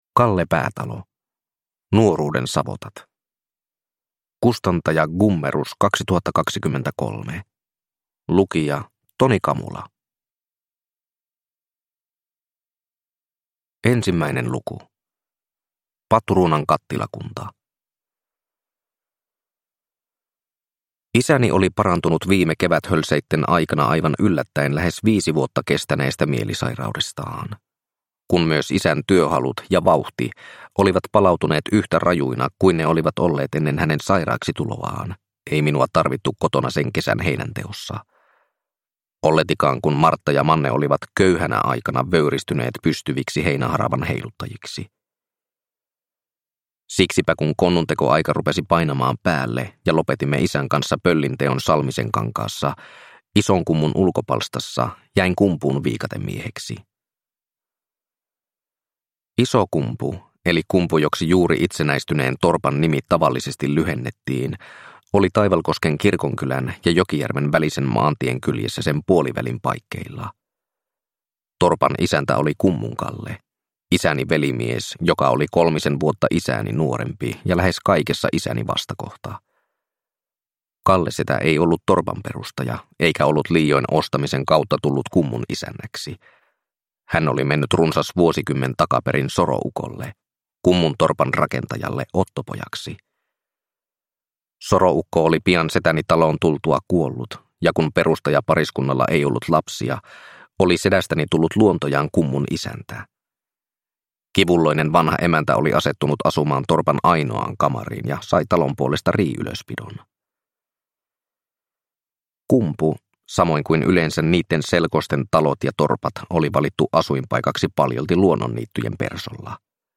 Nuoruuden savotat (ljudbok) av Kalle Päätalo